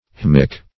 Haemic \H[ae]"mic\ (h[=e]"m[i^]k or h[e^]m"[i^]k), a.